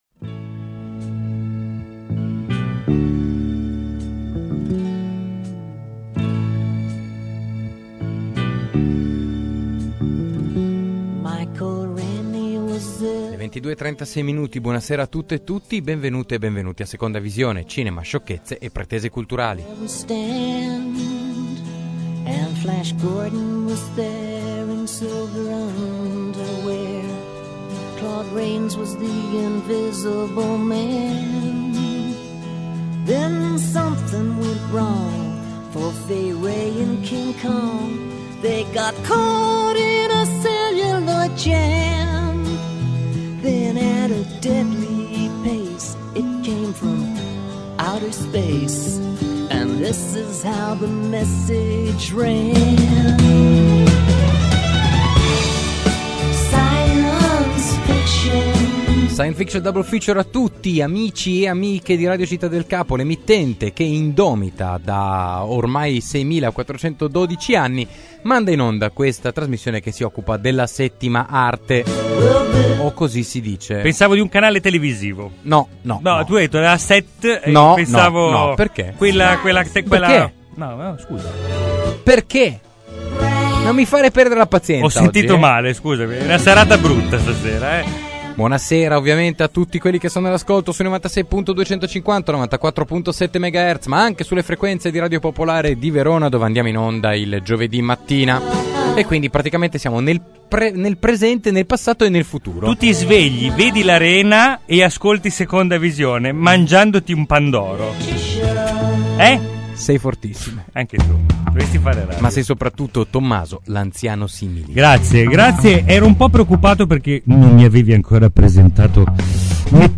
(in collegamento telefonico)